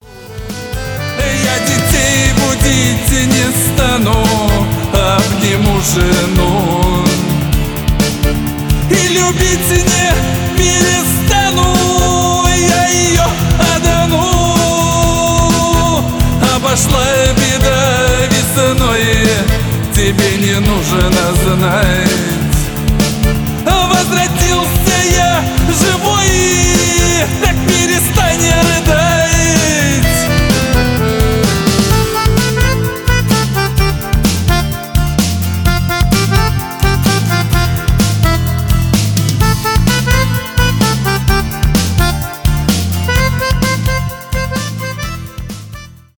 аккордеон , шансон